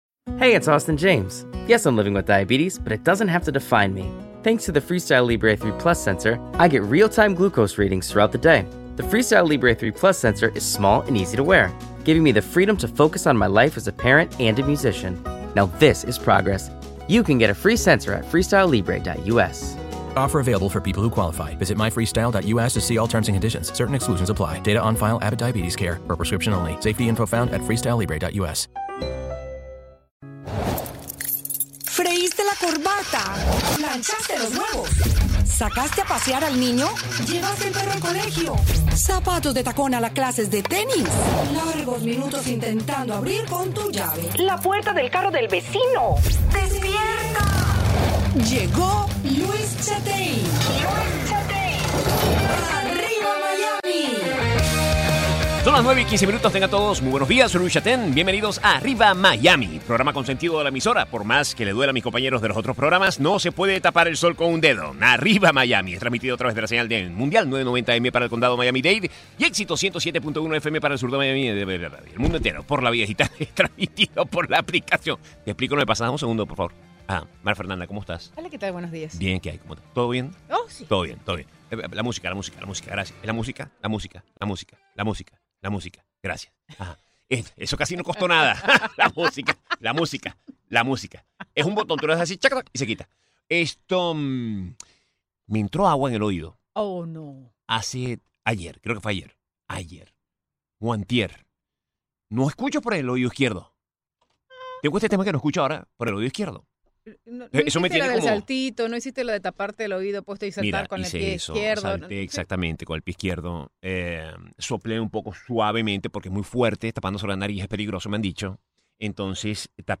Chataing conversa